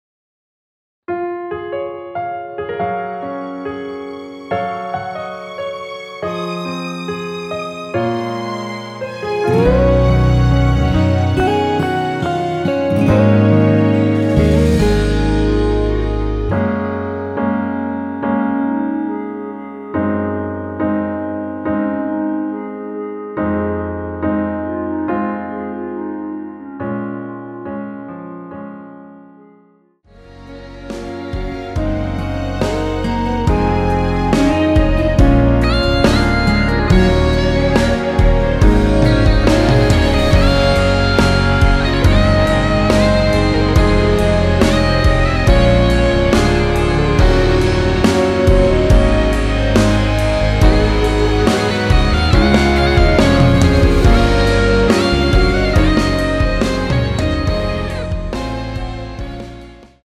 원키에서(-2)내린 멜로디 포함된 MR입니다.
Db
노래방에서 노래를 부르실때 노래 부분에 가이드 멜로디가 따라 나와서
앞부분30초, 뒷부분30초씩 편집해서 올려 드리고 있습니다.